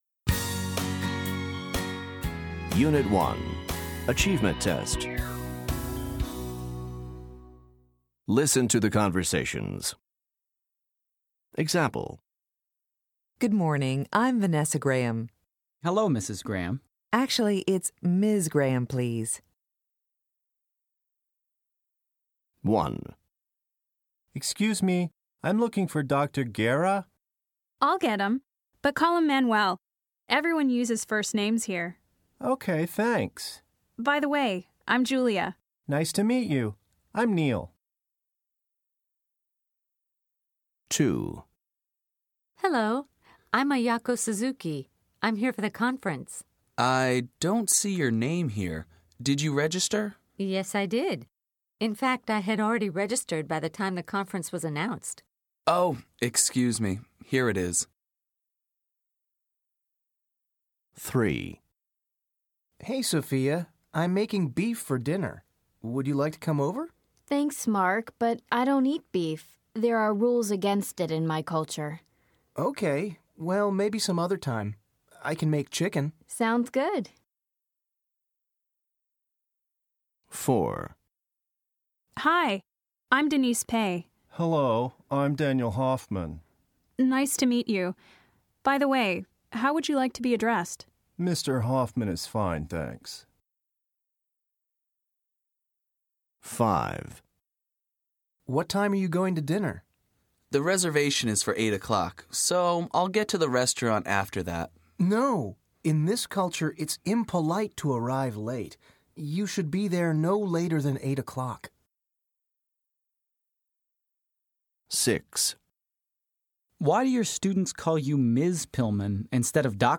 Listen to the conversations. Then choose the word or phrase that correctly completes each sentence.